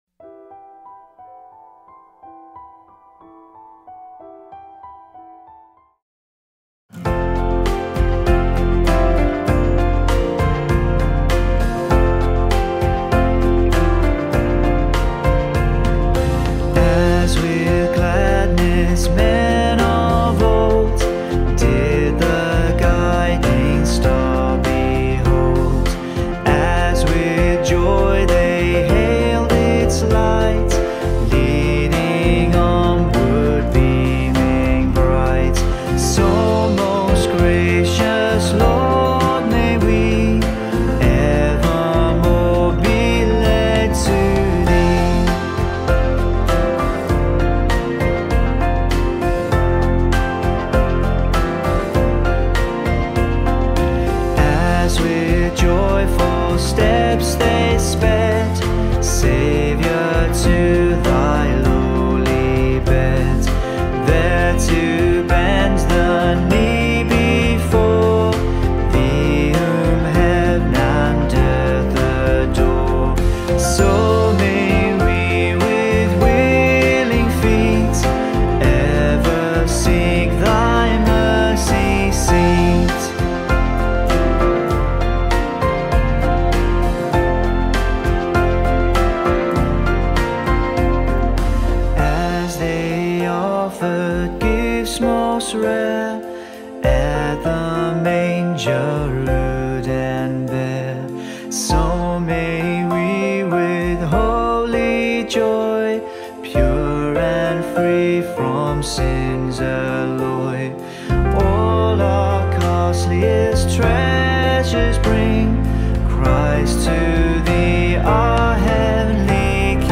Welcome to this time of worship. Our service begins with the singing one of two hymns, the more contemporary song being found here and the traditional hymn being here.